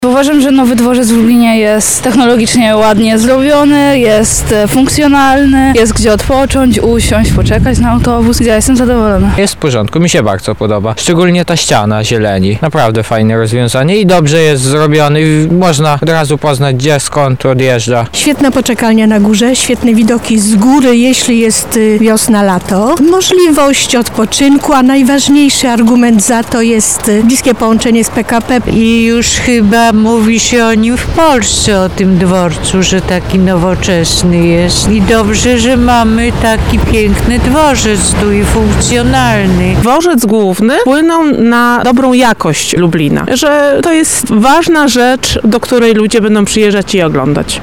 By sprawdzić, czy cena jest adekwatna do jakości, postanowiliśmy spytać mieszkańców naszego miasta, jak sprawuję się on w praktyce.
sonda dworzec